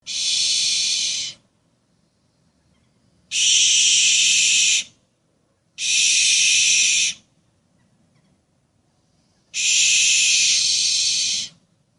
Owl Threateneed
SFX
yt_nQ9mkS3twYk_owl_threateneed.mp3